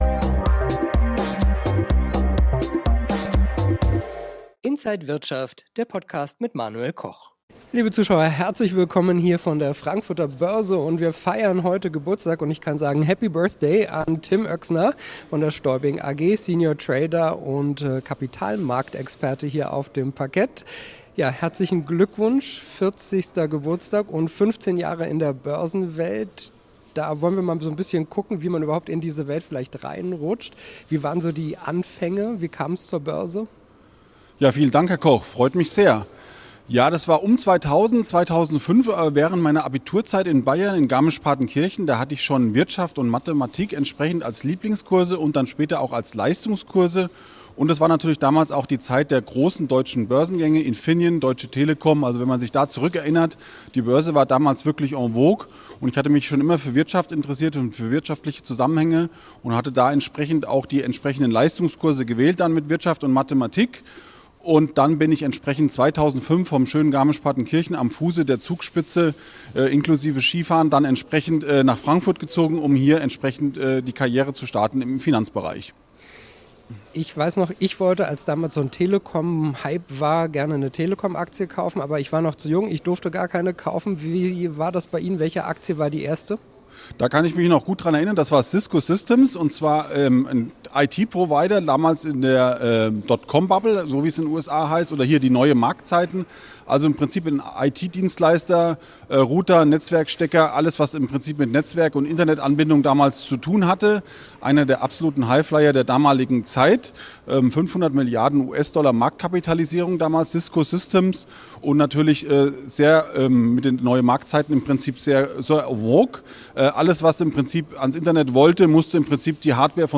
Interview
an der Frankfurter Börse